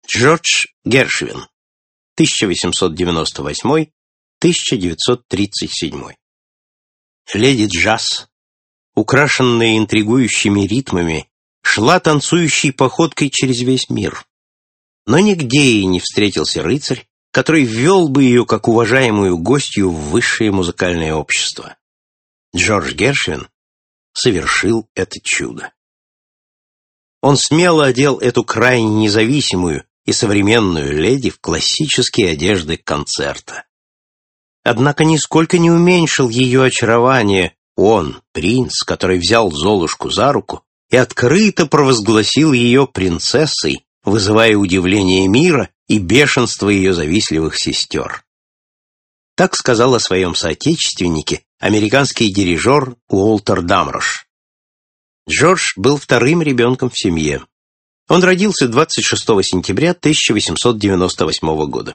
Аудиокнига Великие композиторы | Библиотека аудиокниг